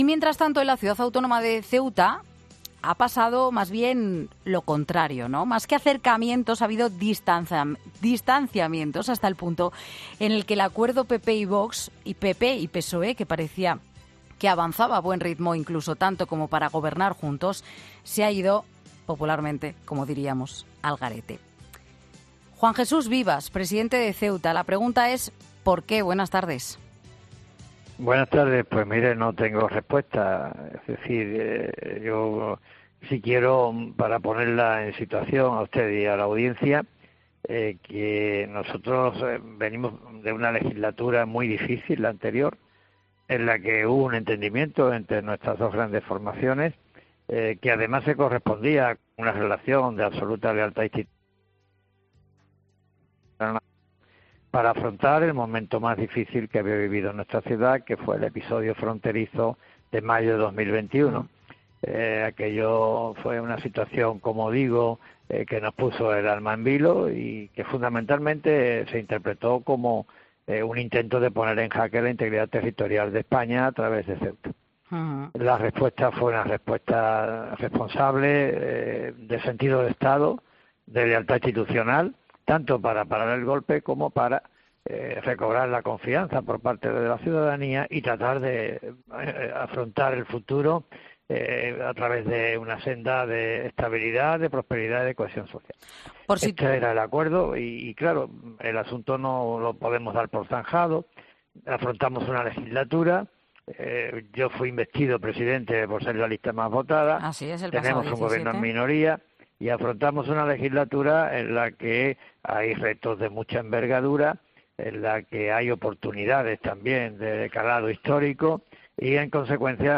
AUDIO: Juan Jesús Vivas, presidente de Ceuta, en los micrófonos de 'Mediodía COPE'